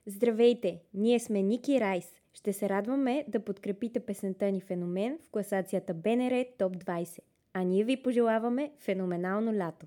интервю за слушателите на БНР Топ 20